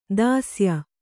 ♪ dāsya